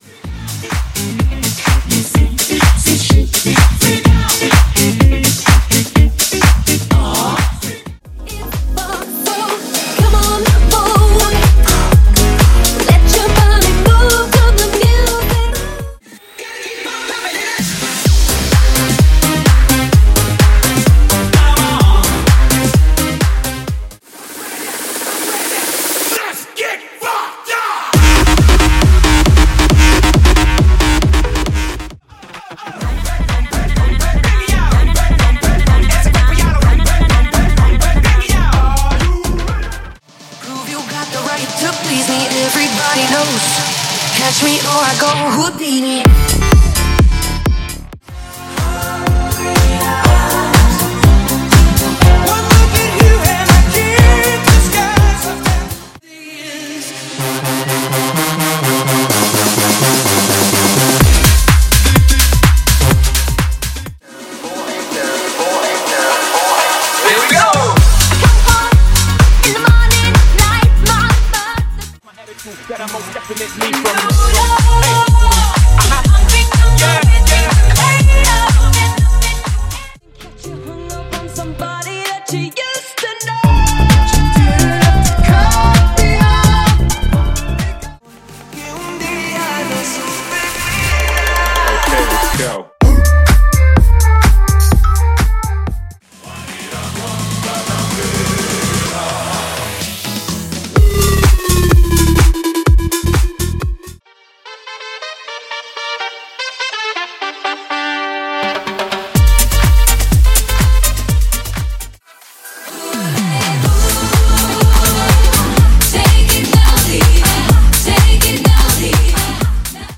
Genre: LATIN
Clean BPM: 105 Time